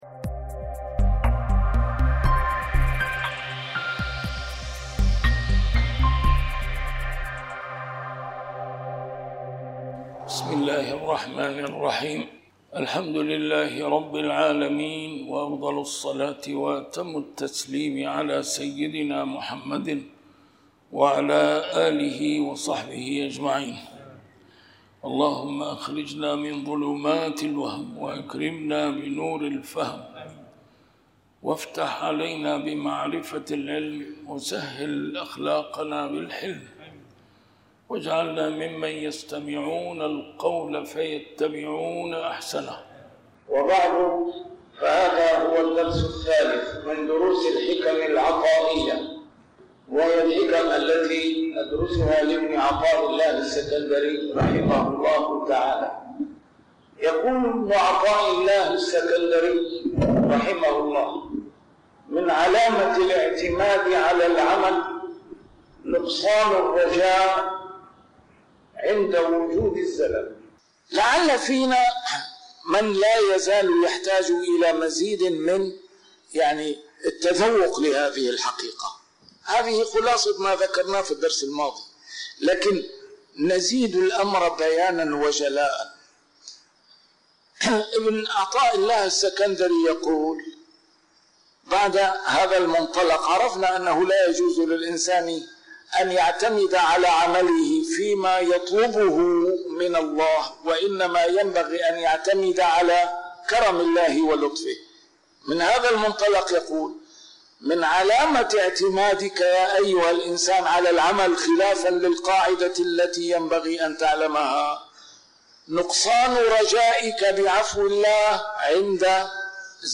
A MARTYR SCHOLAR: IMAM MUHAMMAD SAEED RAMADAN AL-BOUTI - الدروس العلمية - شرح الحكم العطائية - الدرس رقم 3 شرح الحكمة 1